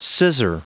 Prononciation du mot scissor en anglais (fichier audio)
Prononciation du mot : scissor